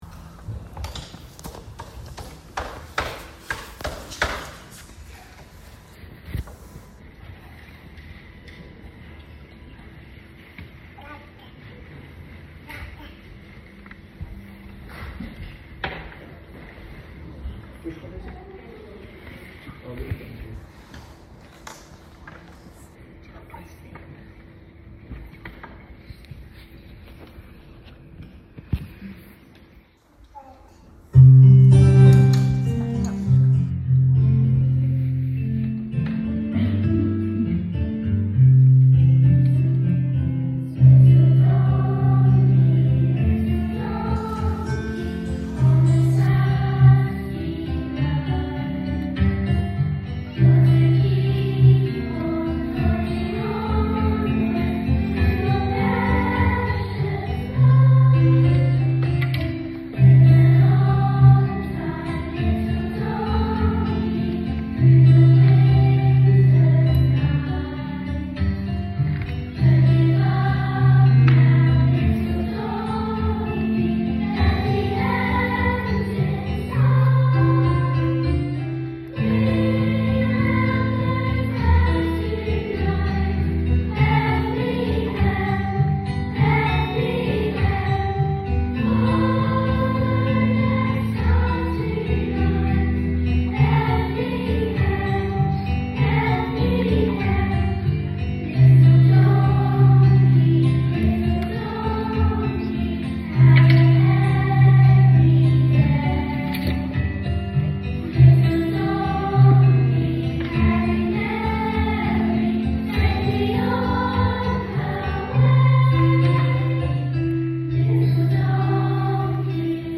Part 2 | Mill Mead Y4-6 Choir